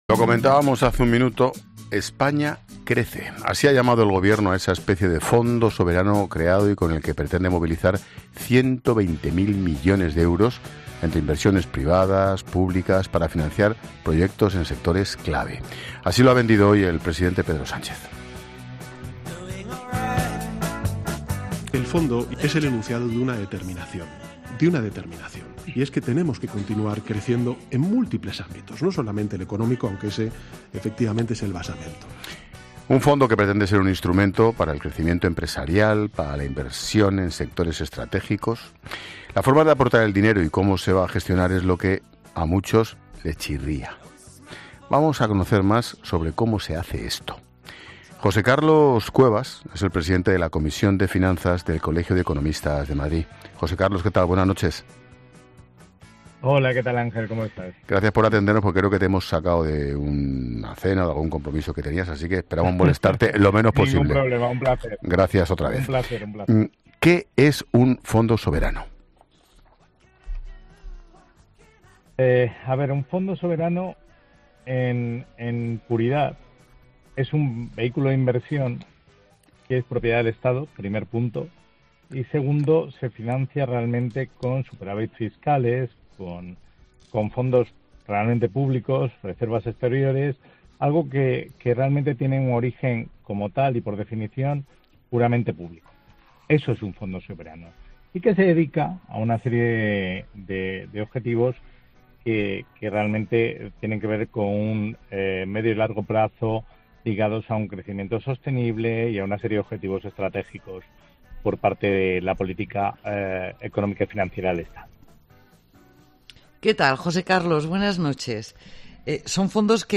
En la entrevista también se ha recordado la gestión de los fondos europeos Next Generation, de los cuales España no ha logrado absorber una parte importante, lo que añade escepticismo sobre la capacidad de ejecución de este nuevo instrumento.